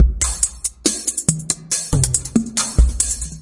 标签： 70 bpm Ambient Loops Vocal Loops 607.84 KB wav Key : Unknown
声道立体声